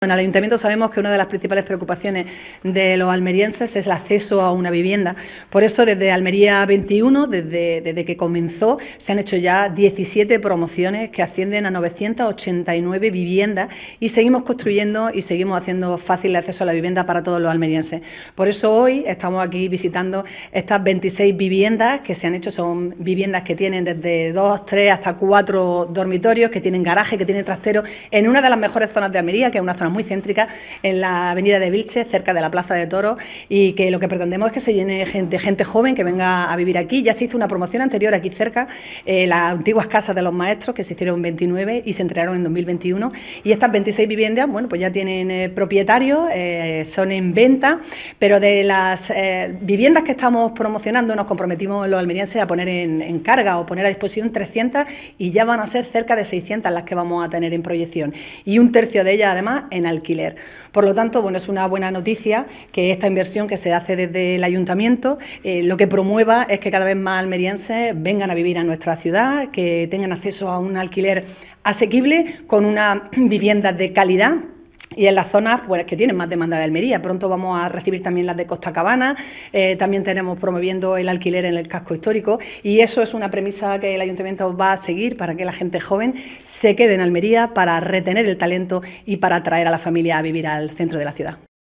ALCALDESA-VISITA-FIN-DE-OBRA-PROMOCION-VPO-AVENIDA-VILCHES.wav